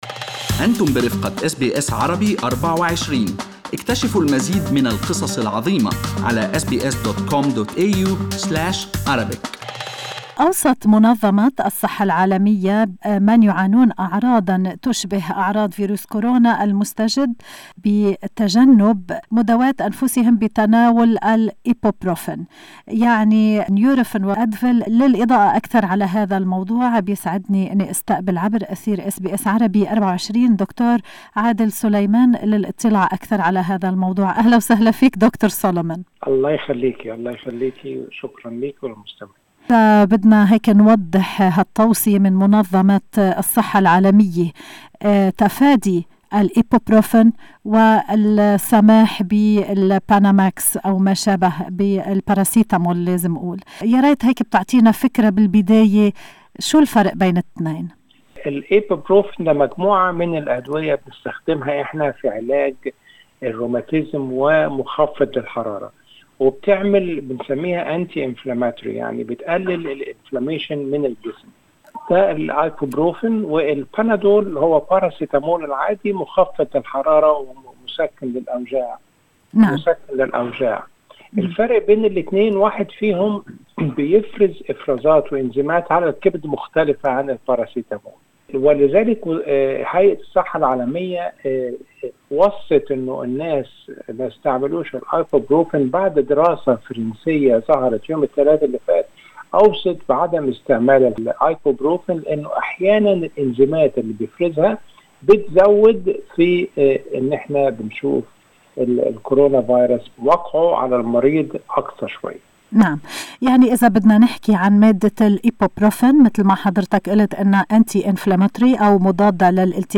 خبير طبي